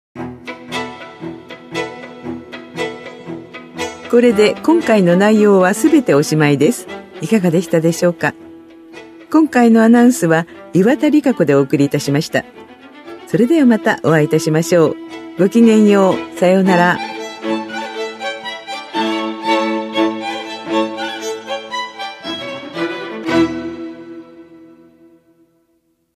声の区議会だより（音声データ）
練馬区議会では、目の不自由な方のために、デイジーによる「声の区議会だより」を発行しています。